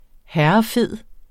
Udtale [ -ˌfeðˀ ]